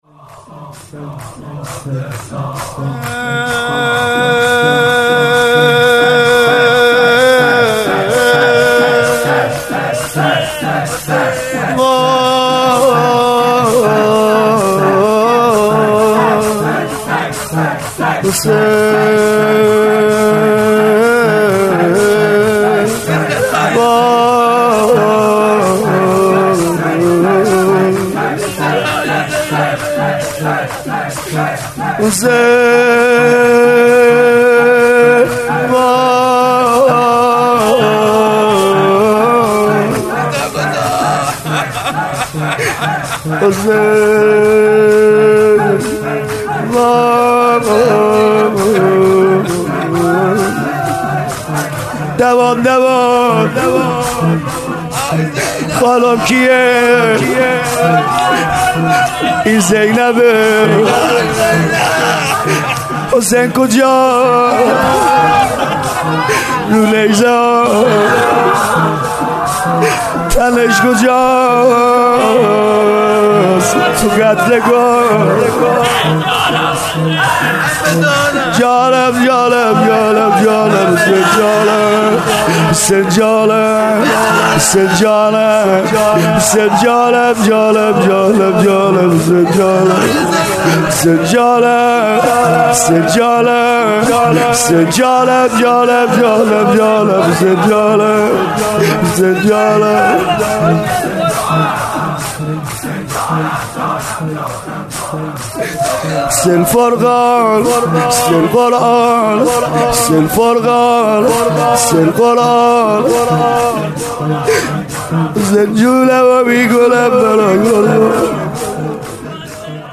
مراسم شب دوم محرم ۹۵
اصفهان/مسجدمحمدیه